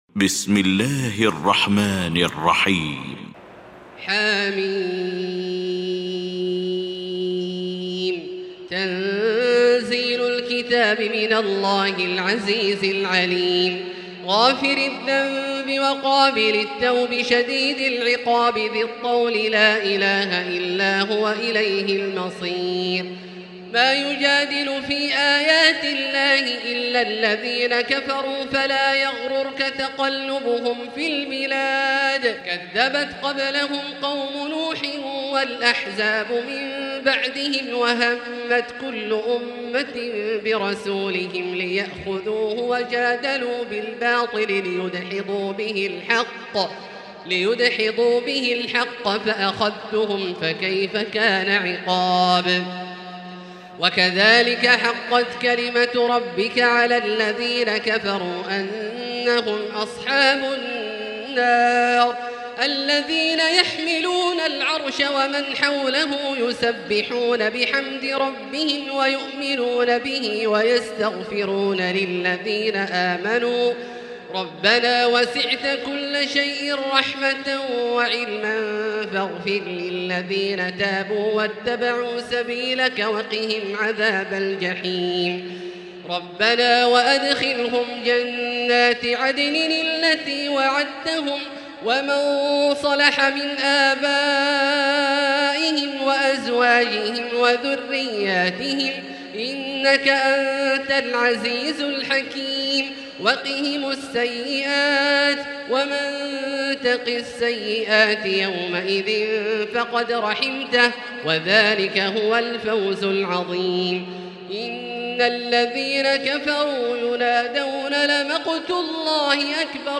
المكان: المسجد الحرام الشيخ: فضيلة الشيخ عبدالله الجهني فضيلة الشيخ عبدالله الجهني فضيلة الشيخ ياسر الدوسري غافر